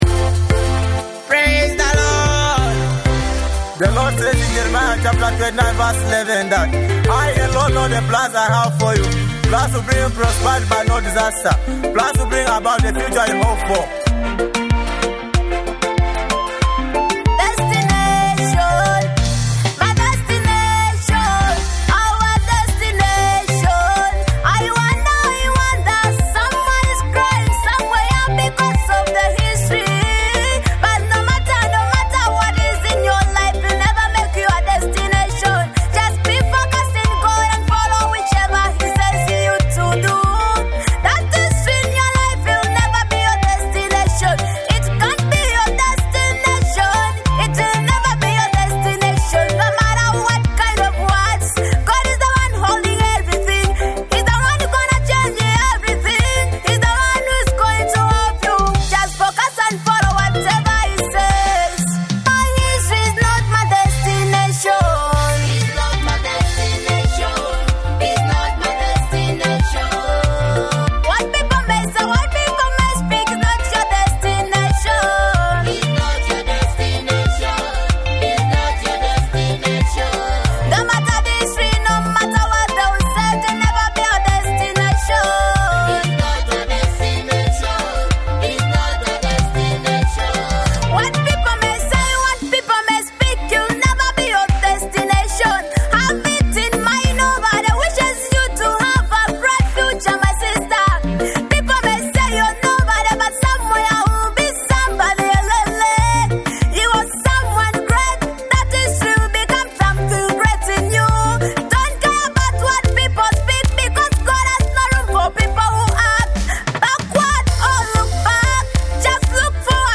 gospel anthem